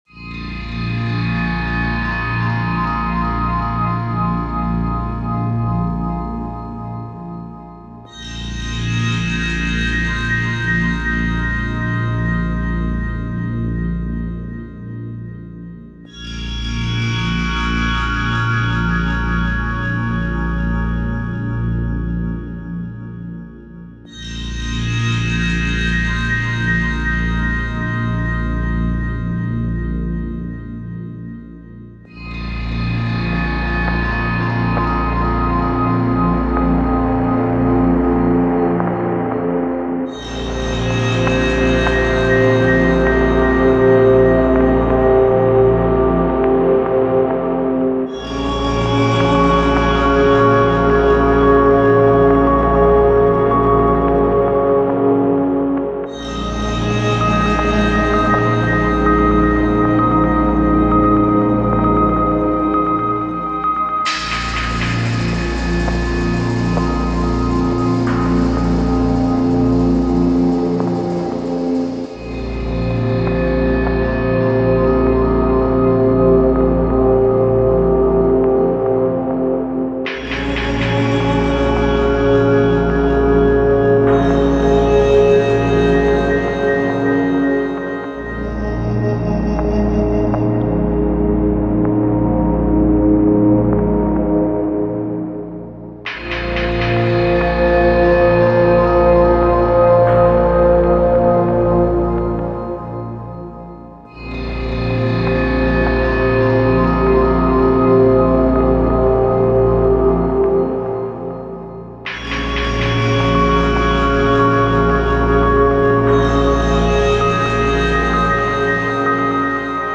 Ambient Chill Out / Lounge Cinematic / FX
A#m 120 BPM